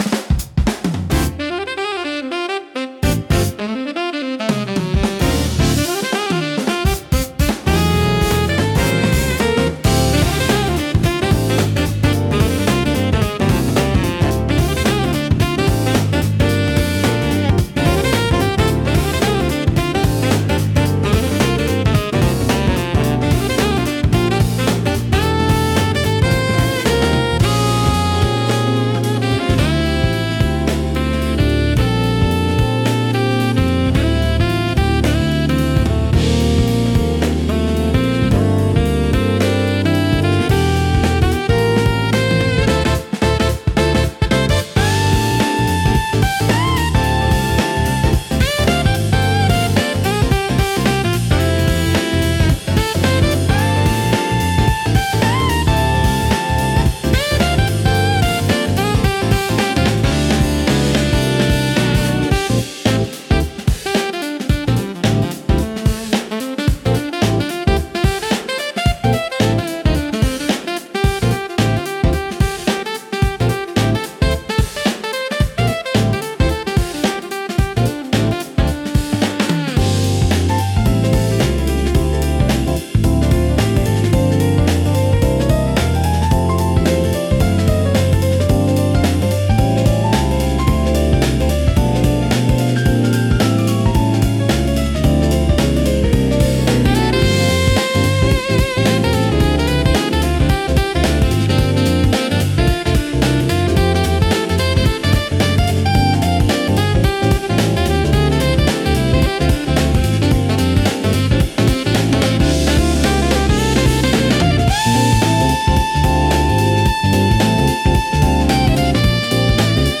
スムースジャズは、ジャズの要素を基にしつつも、よりメロディアスで柔らかく心地よいサウンドを追求したジャンルです。
軽やかなリズムと滑らかなサックスやギターのメロディが特徴で、都会的でリラックスした雰囲気を演出します。
落ち着いた空気感を作り出しつつも、聴き疲れしにくい快適さがあり、ゆったりとした時間を楽しみたいシーンで多く活用されます。